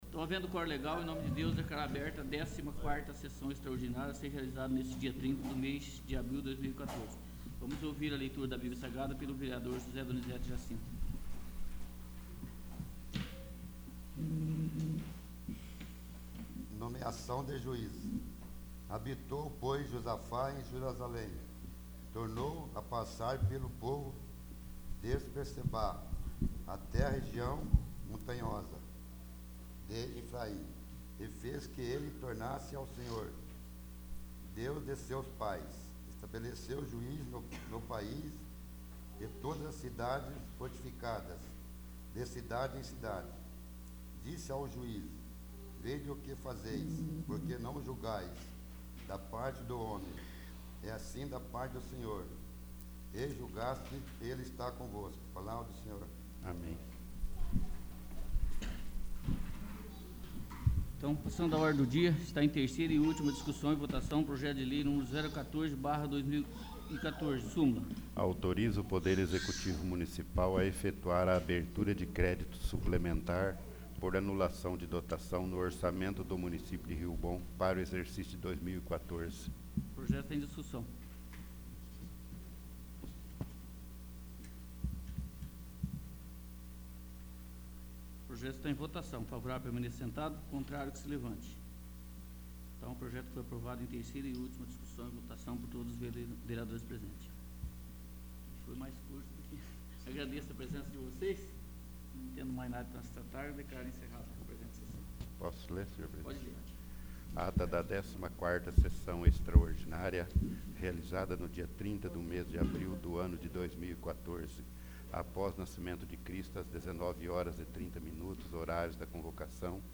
14º. Sessão Extraordinária